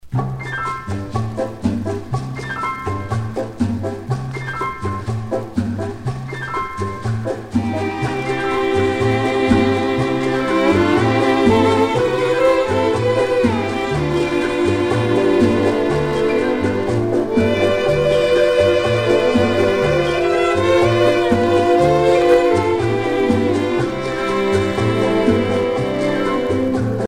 rumba
Pièce musicale éditée